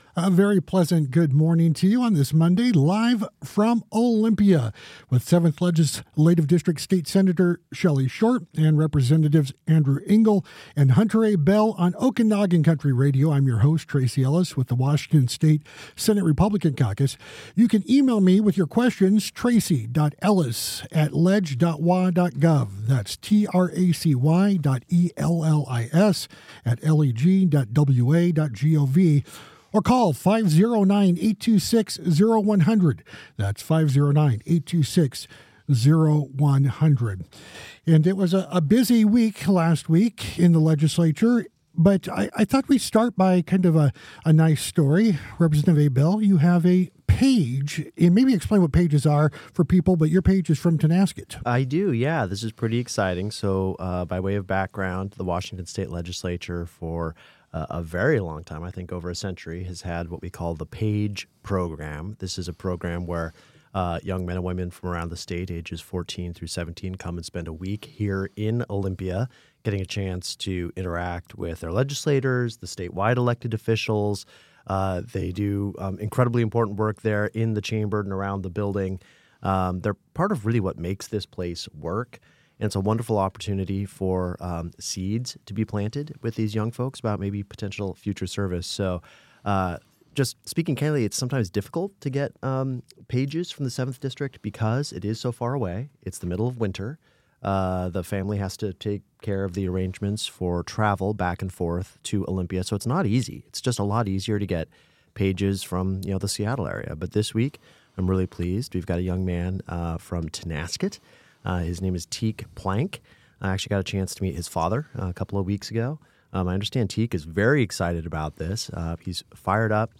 AUDIO: Live from Olympia: Legislative Updates with Senator Shelly Short and Representatives Andrew Engell & Hunter Abell